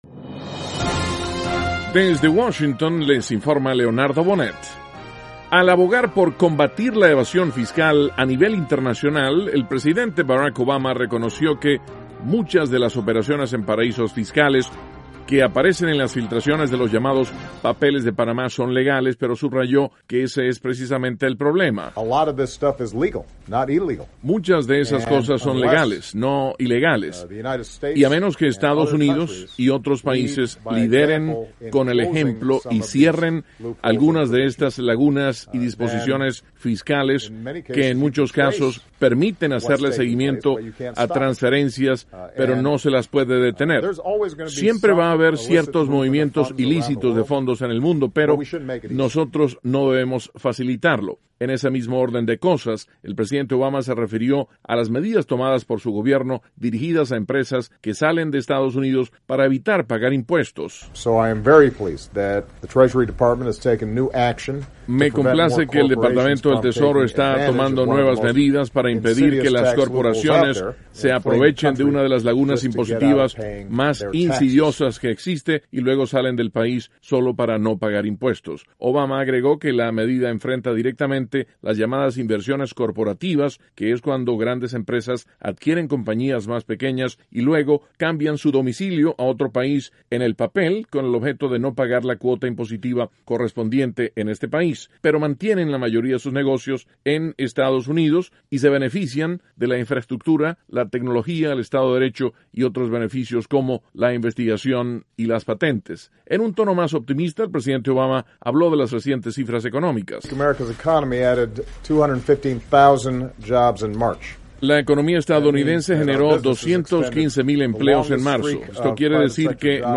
VOA: Noticias de la Voz de América - Martes, 5 de abril, 2016